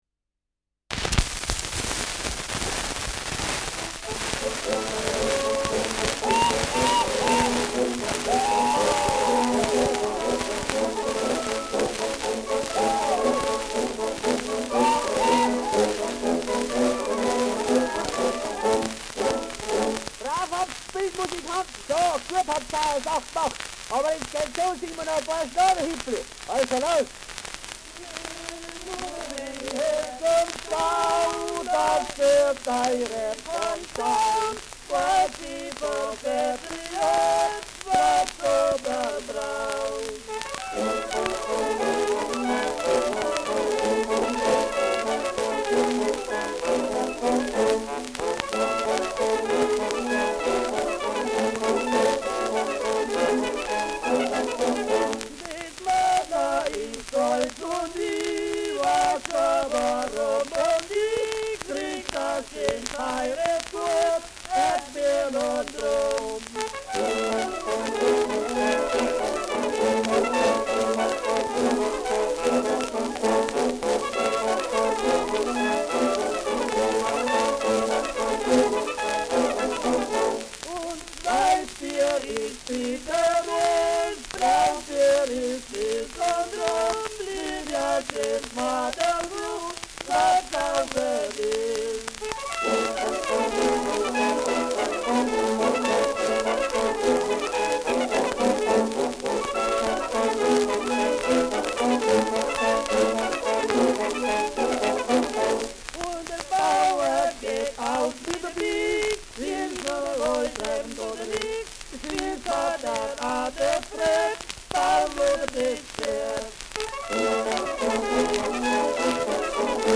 Schellackplatte
Starkes Grundrauschen : Durchgehend leichtes bis stärkeres Knacken : Starkes Nadelgeräusch im letzten Drittel
Mit Juchzern.
[Ansbach] (Aufnahmeort)
Humoristischer Vortrag* FVS-00003